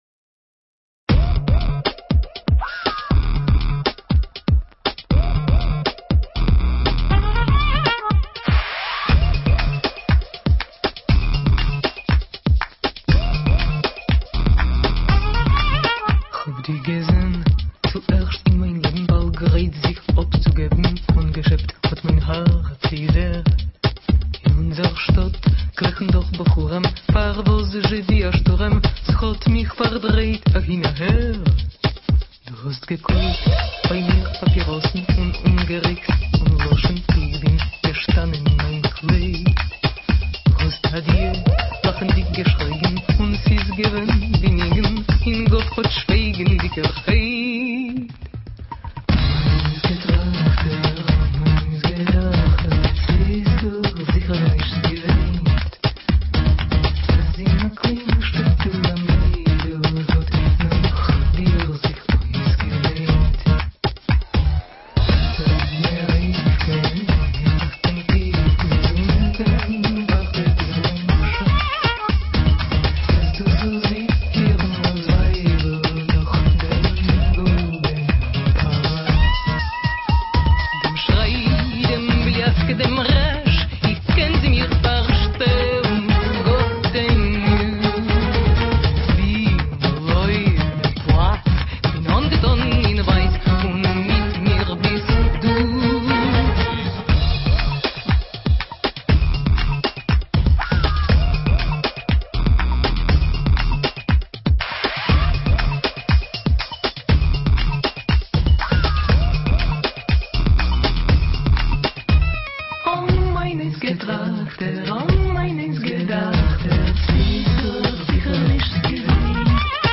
Теперь это уже не танго.